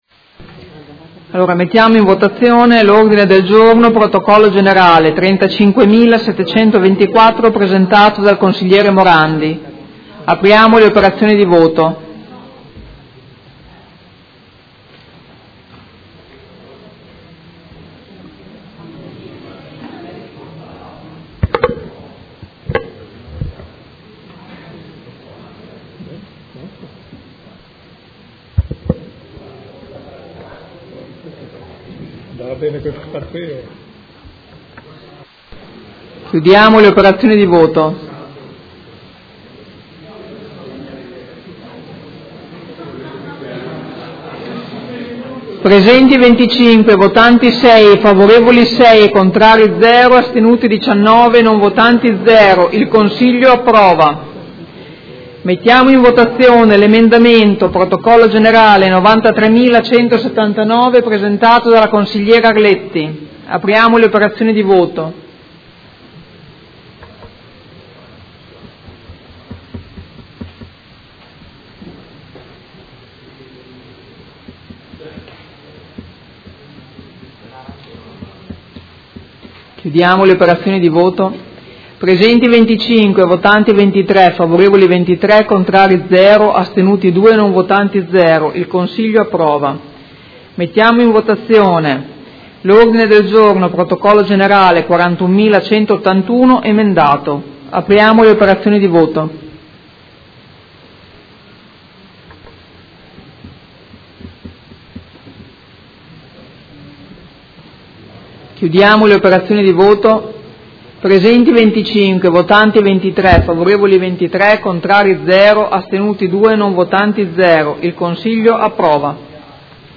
Presidente — Sito Audio Consiglio Comunale
Presidente